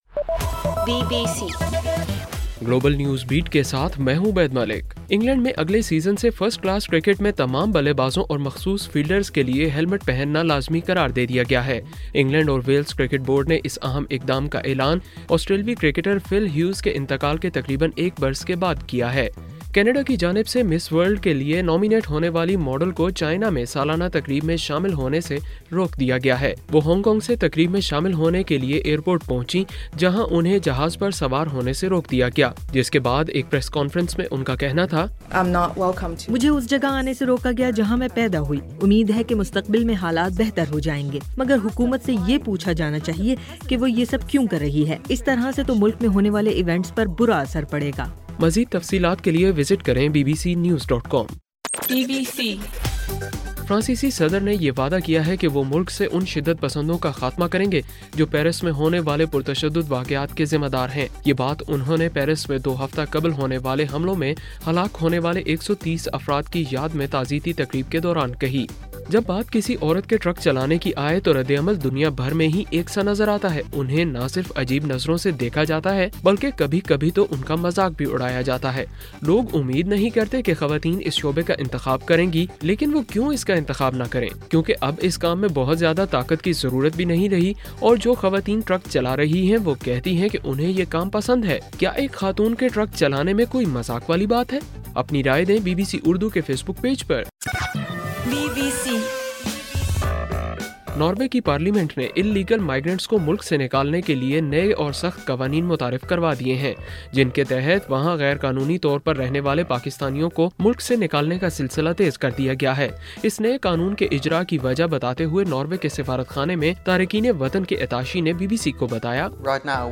نومبر 27: رات 9 بجے کا گلوبل نیوز بیٹ بُلیٹن